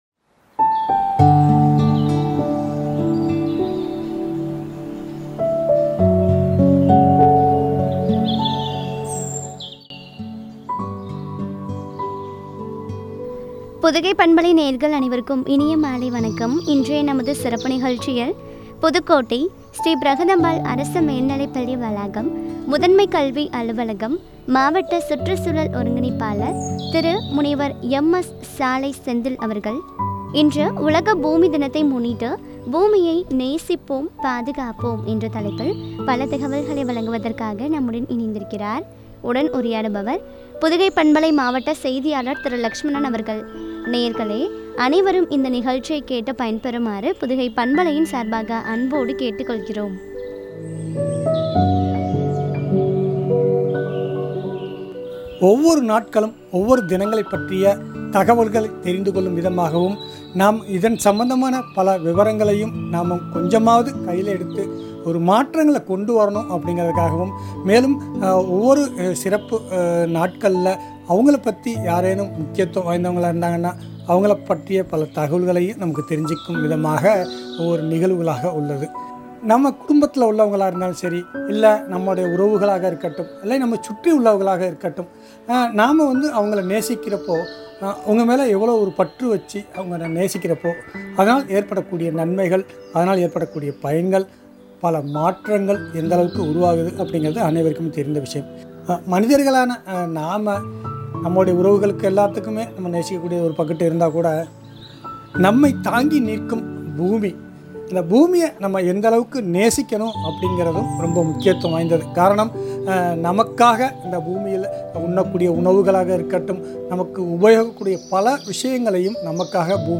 பாதுகாப்போம் என்ற தலைப்பில் வழங்கிய உரையாடல்.